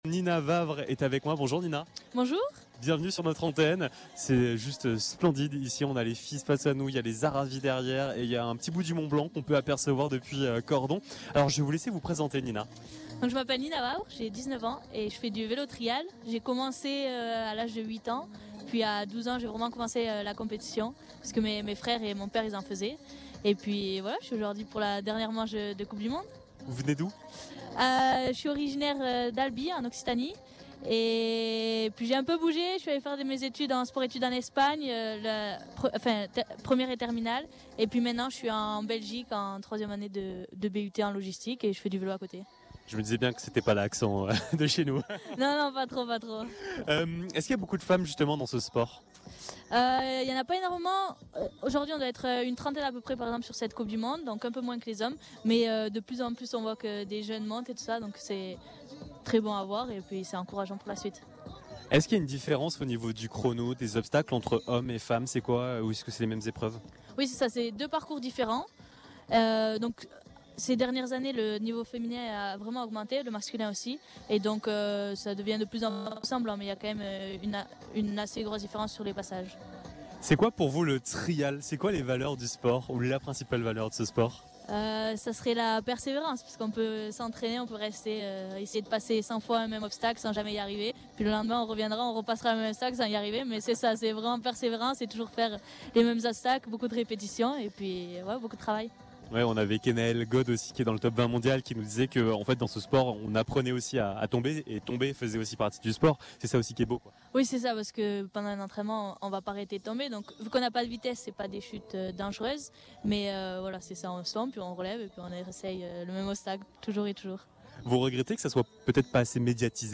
À l’occasion de la Coupe du Monde de VTT Trial, nous étions en direct de Cordon pour une émission spéciale en direct du cœur de l’événement.
Interview